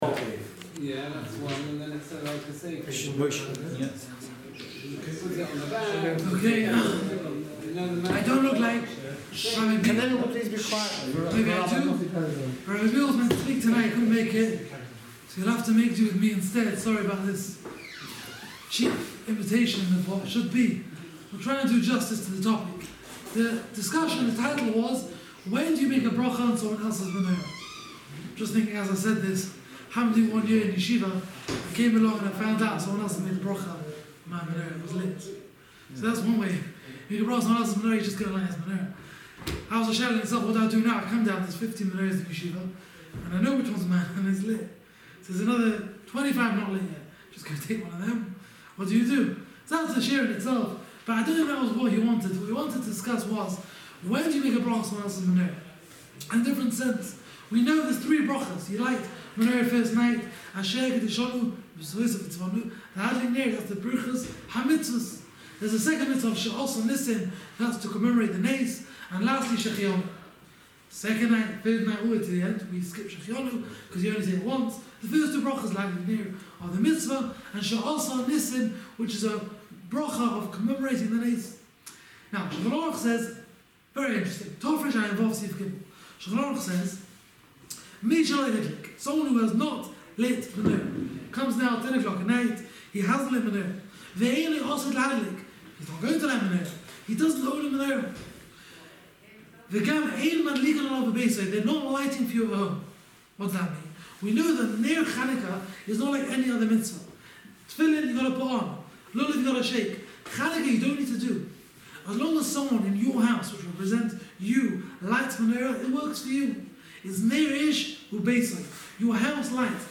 Start Your Day The TorahWay Manchester provides daily shiurim on a wide range of topics.